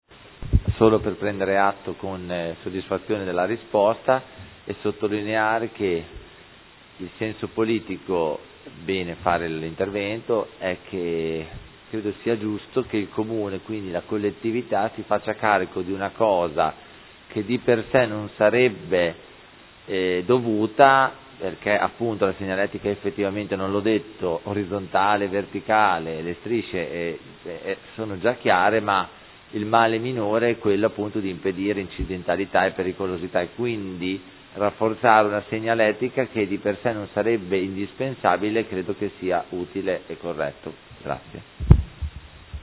Seduta del 29/10/2015 Replica. Interrogazione del Consigliere Carpentieri (P.D.) avente per oggetto: Viabilità su Via Divisione Acqui – Strada Fossamonda centro